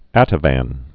(ătə-văn)